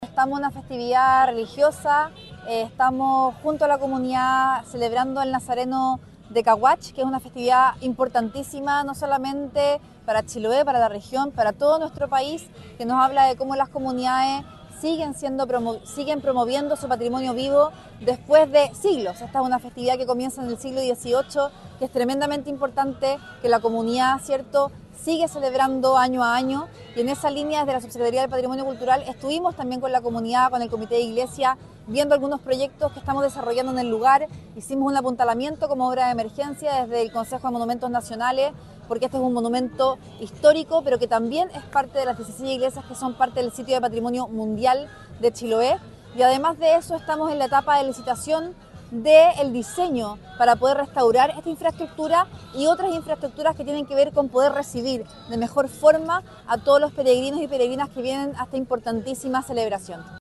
En la oportunidad, encabezó esta delegación la Subsecretaria de Patrimonio Cultural, del Ministerio de Las Culturas, Las Artes y el Patrimonio, Carolina Pérez Dattari, quien relevó la importancia de las iniciativas que desde la cartera se han estado impulsando para poder enfrentar el deterioro que afecta a la Iglesia de Caguach, Patrimonio de la Humanidad.